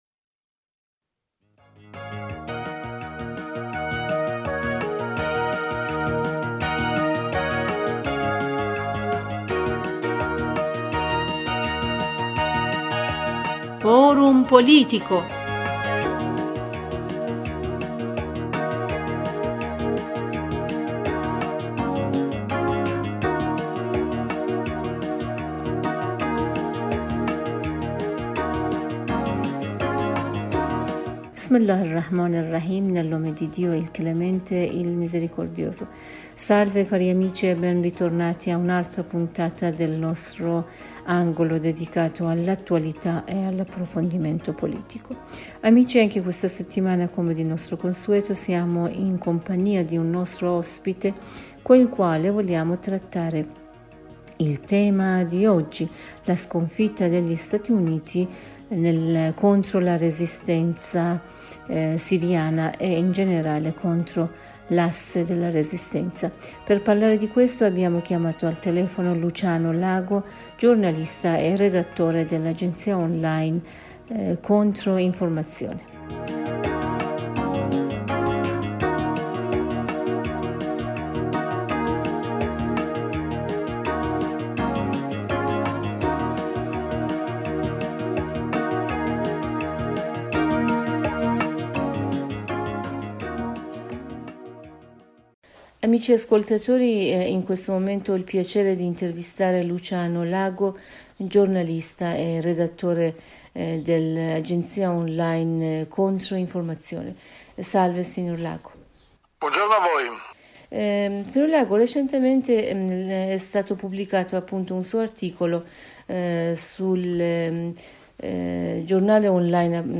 Per ascoltare la versione integrale dell'intervista cliccare qui sopra: